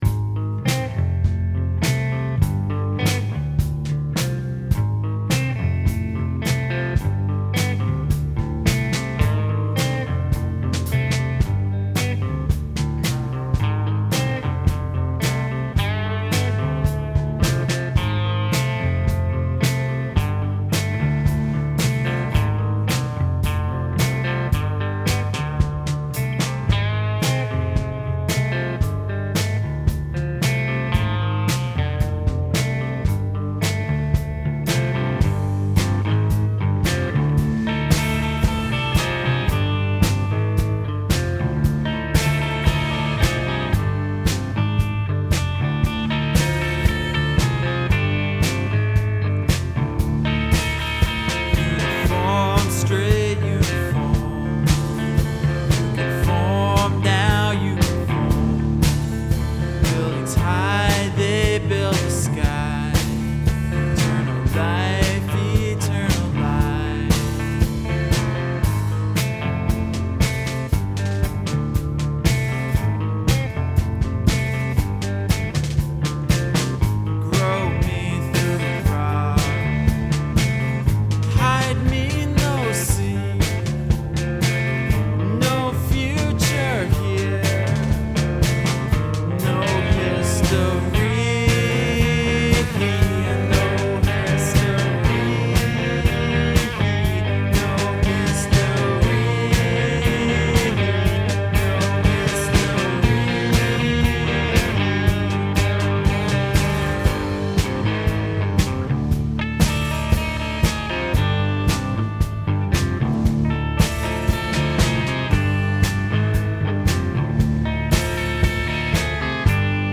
fostex E-16